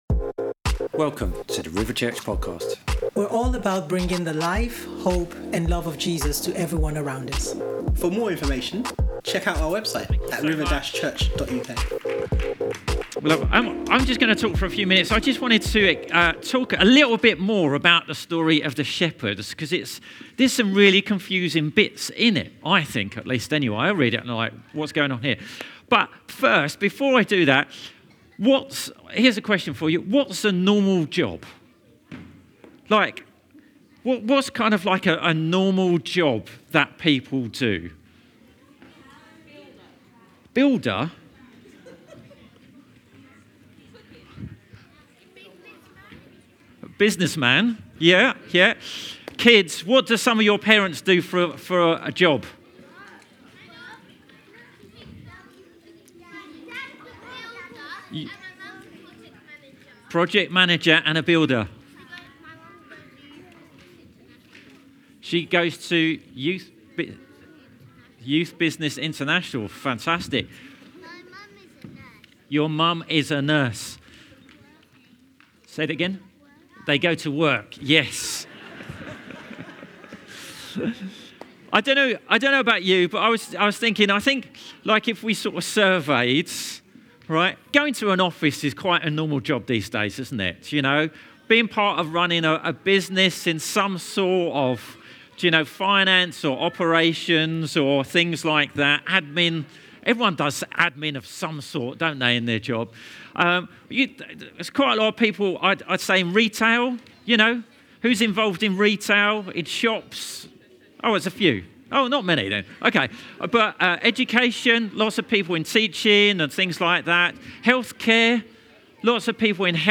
Christmas Family Service 2025